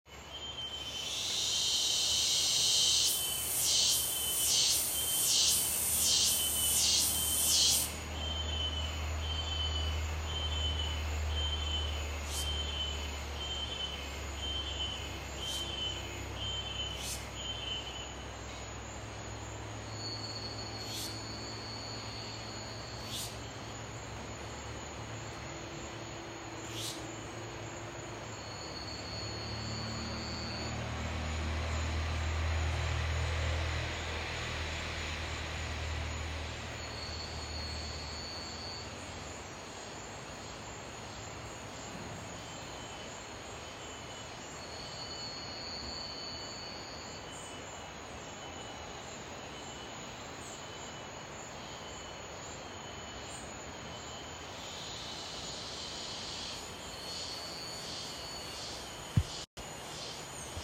In a small pueblo called San Carlos you’re never alone for the constant murmur of the Cicadas.